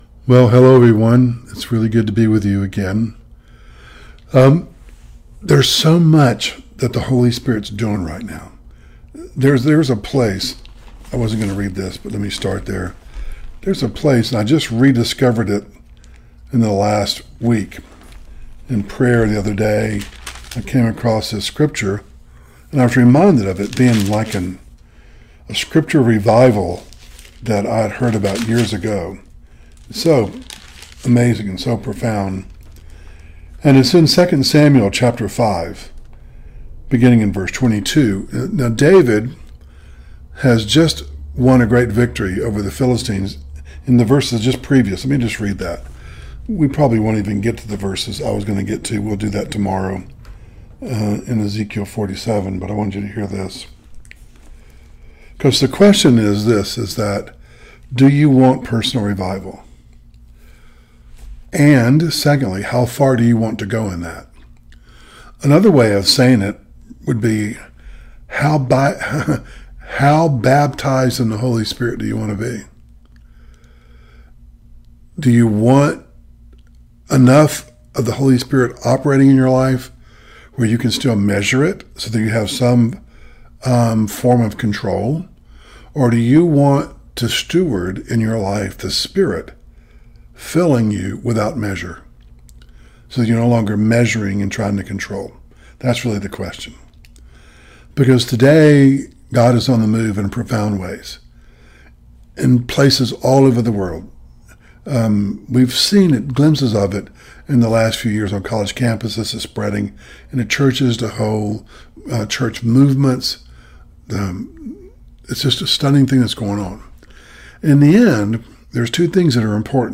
Service Type: Devotional